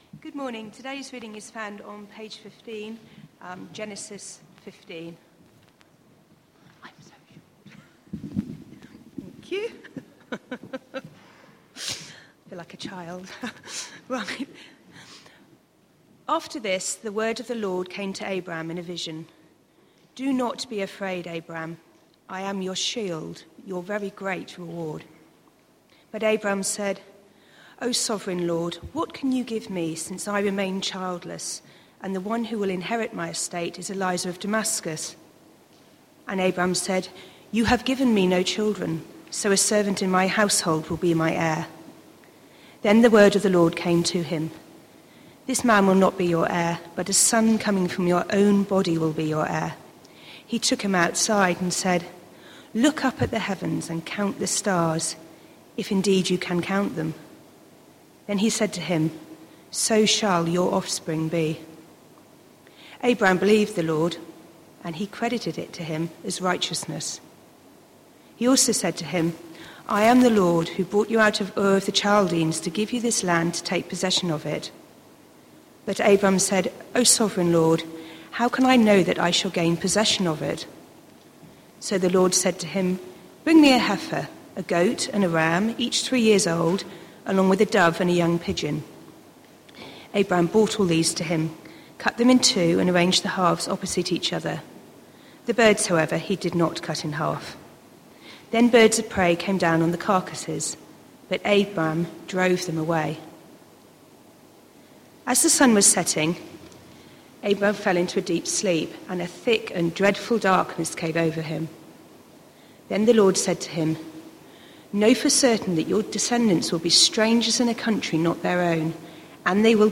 Christ Church Morning Service
What are the sacraments for? Theme: Sermon All music is licensed by Podcast/RSS FEED The media library is also available as a feed, allow sermons to be automatically downloaded to your PC or smartphone.